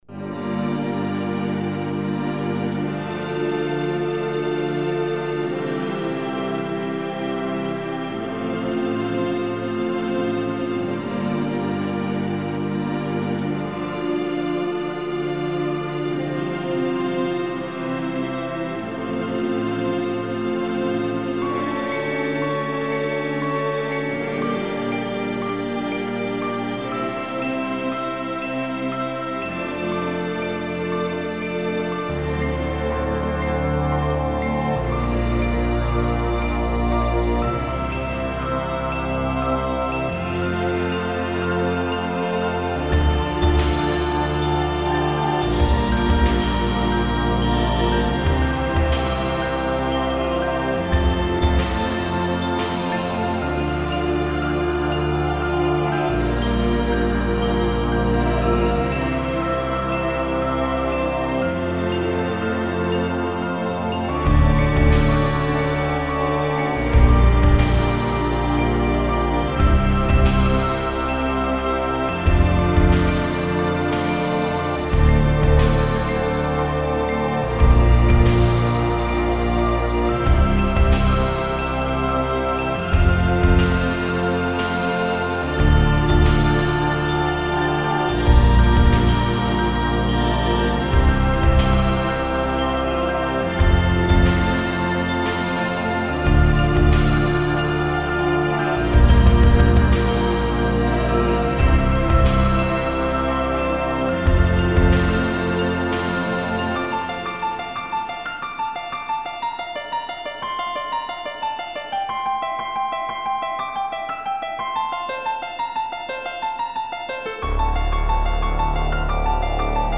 4:05 – Dark Ballad / Ambient-Electronic-Symphonic.